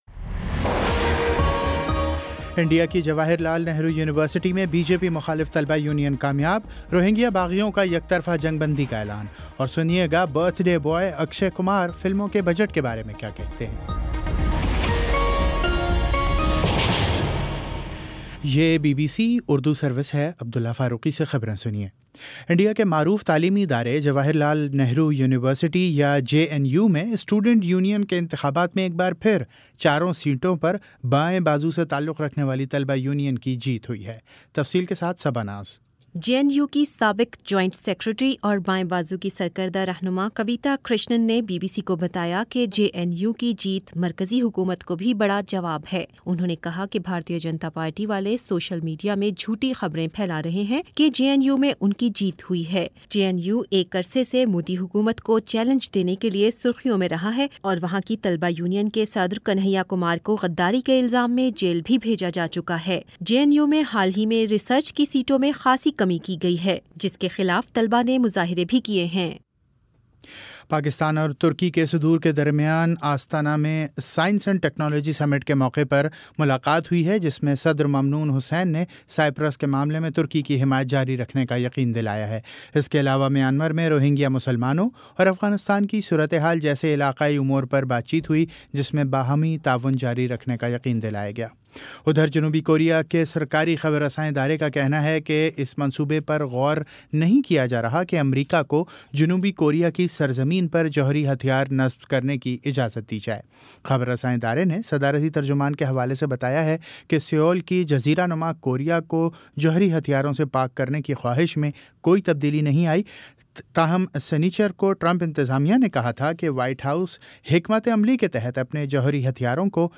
ستمبر 10 : شام پانچ بجے کا نیوز بُلیٹن